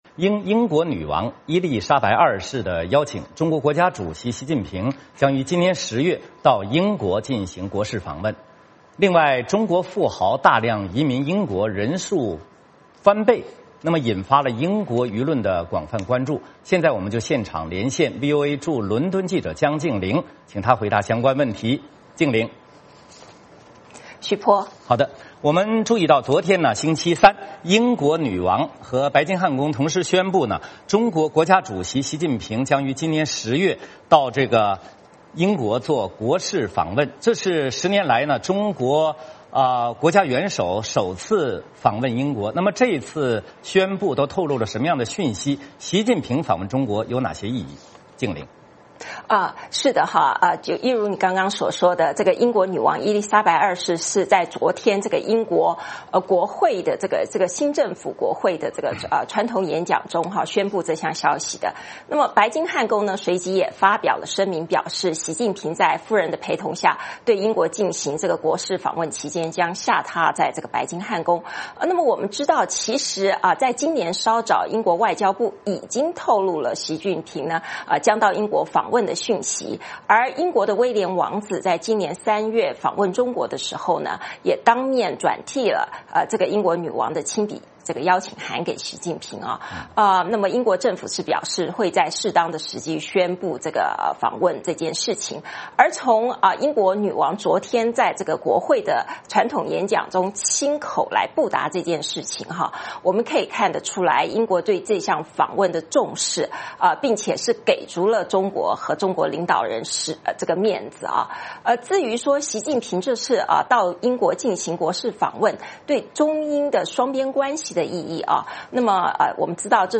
VOA连线：英国宣布习近平10月对英进行国事访问